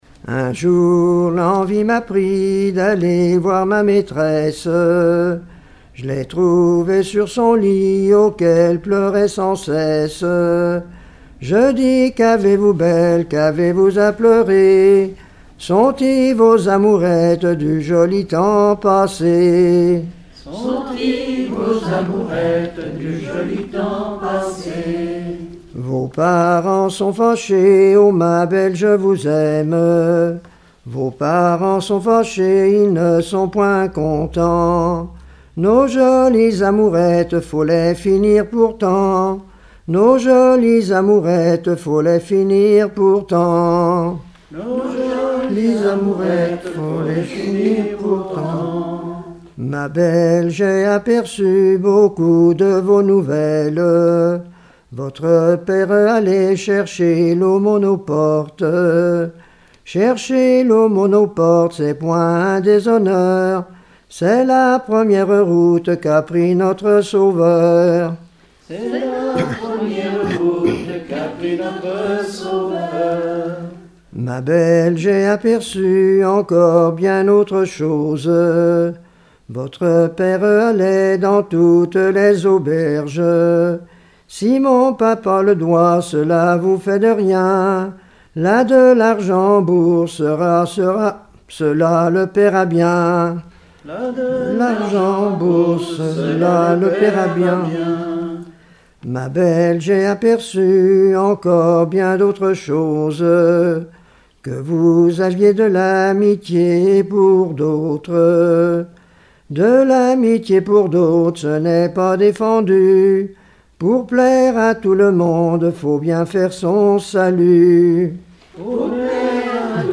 Genre strophique
Collectif-veillée (2ème prise de son)
Pièce musicale inédite